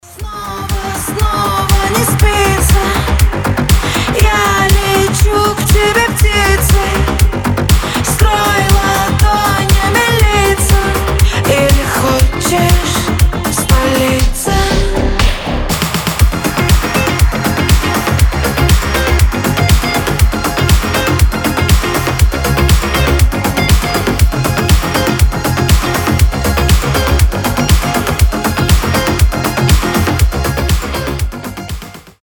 • Качество: 320, Stereo
ритмичные
Synth Pop
женский голос
house